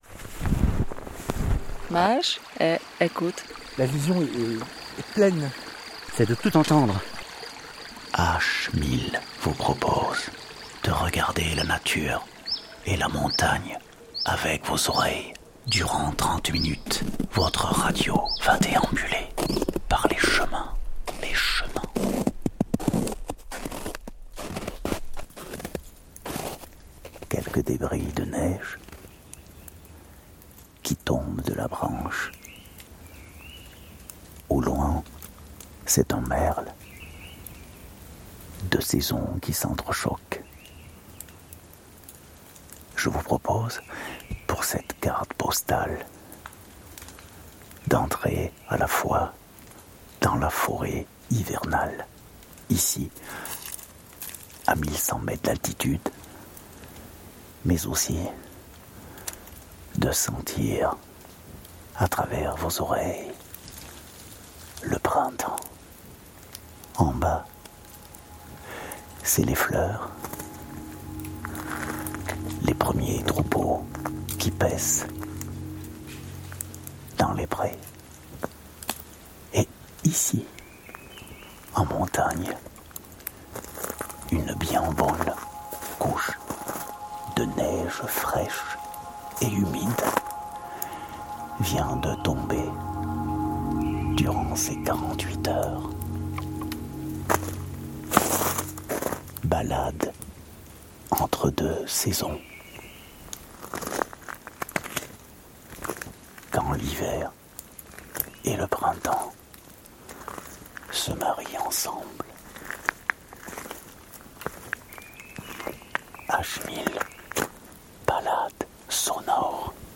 H1000 propose, micro en main, une randonnée acoustique à travers les chaînes montagneuses.
Cette semaine dans H1000, découvrez une carte postale auditive autour du printemps !
Quelque soit le site ou la saison, H1000 fait traverser à l’auditeur un paysage sonore où se mêlent les sons des grands espaces montagnards et les impressions des personnages qui le traversent. Crampons, piolets, interviews de randonneurs rencontrés au hasard du chemin, faune, flore…